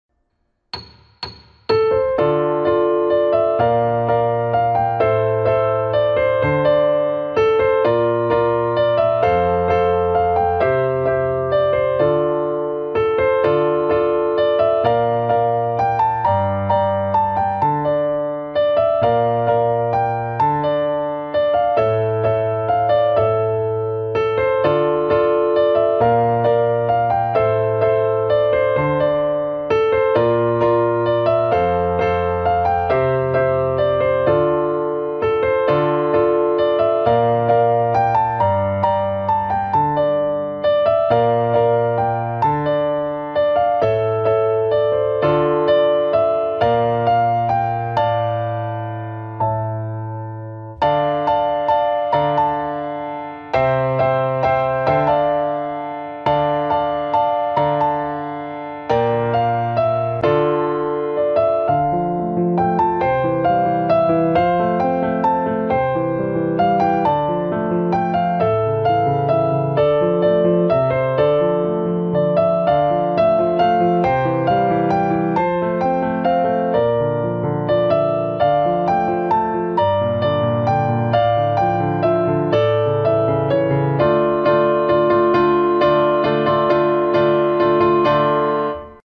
Pirati-3.-vysledek-125-metronom.mp3